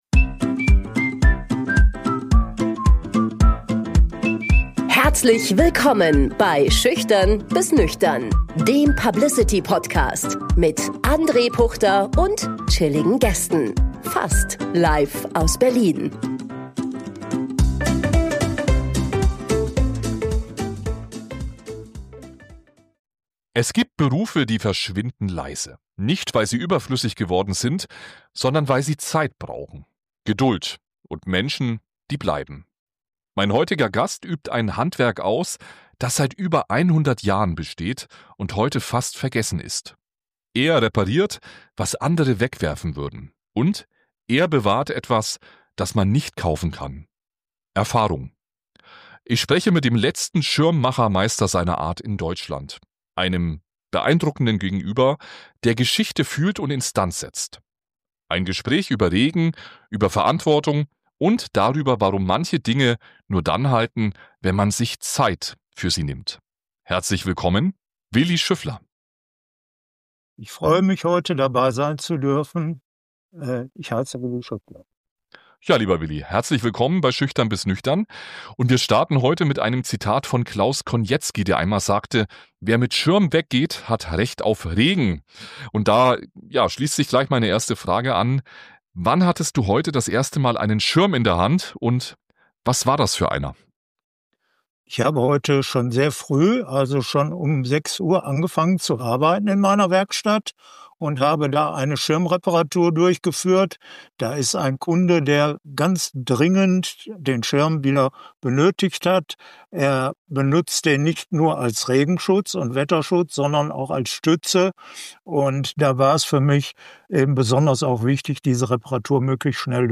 Ein Gespräch über Regen, über Verantwortung und darüber, warum manche Dinge nur dann halten, wenn man sich Zeit für sie nimmt.